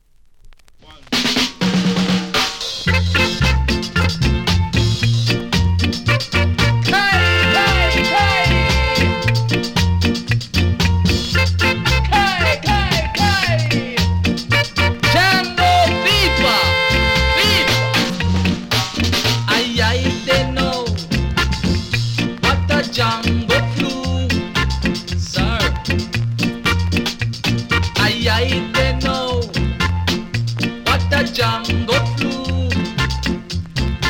7inch
両面盤の見た目は悪いですが音は良好です。